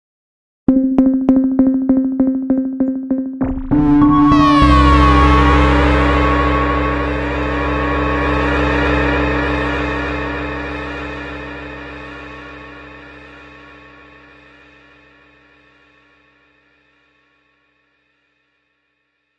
这是一个奇怪的电子效应loog，是由Waldorf Attack VST Drum Synth创建的。
标签： 回路 怪异 电子 C onstructionKit 舞蹈 120BPM 科幻 有节奏
声道立体声